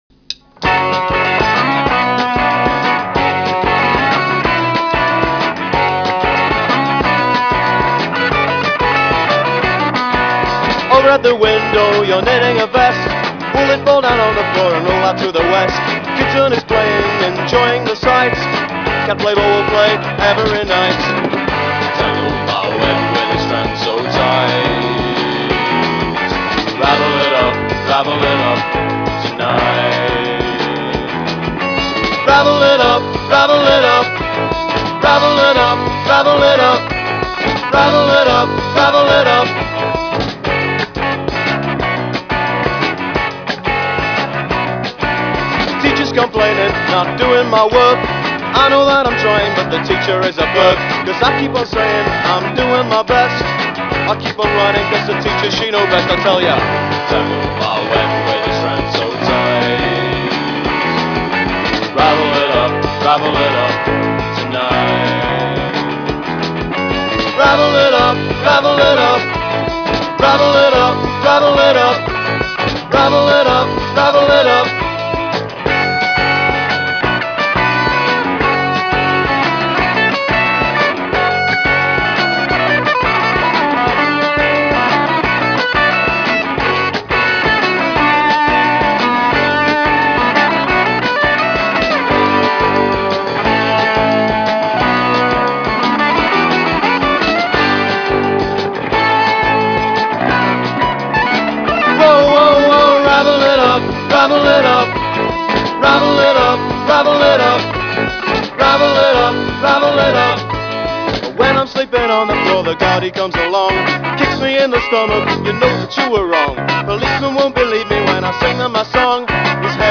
激rare punk/power pop